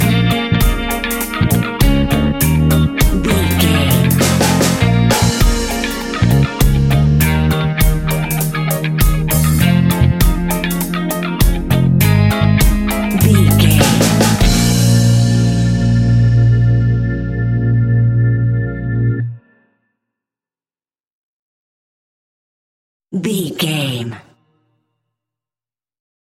Aeolian/Minor
B♭
reggae instrumentals
laid back
chilled
off beat
drums
skank guitar
hammond organ
percussion
horns